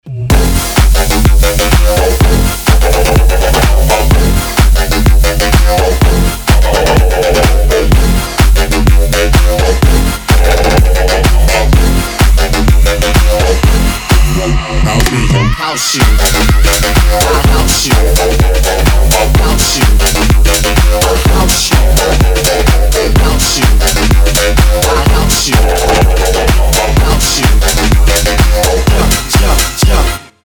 • Качество: 320, Stereo
ритмичные
заводные
dance
club
electro house
бас
vocal